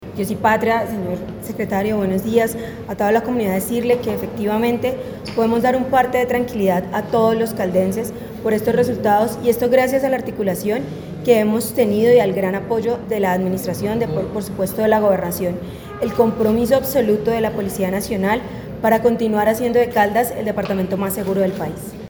Coronel Rocío Milena Melo Puerto, comandante del Departamento de Policía Caldas.
Coronel-Rocio-Milena-Melo-Puerto-comandante-del-Departamento-de-Policia-Caldas-Consejo-seguridad.mp3